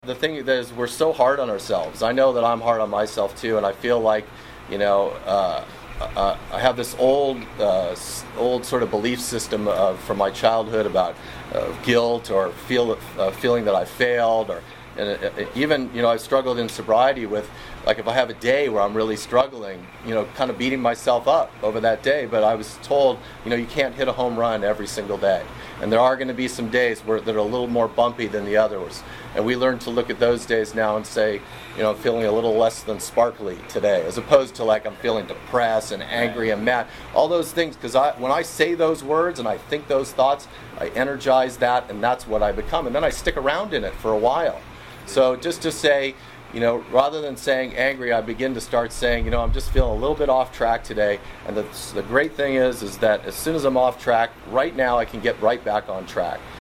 In this group meeting, speakers share personal experiences of loneliness and the impact of addictionism, highlighting the relief and hope found through the program of recovery and support. Central to the discussion is the transformative role of a higher power, which provides guidance and strength in the recovery process.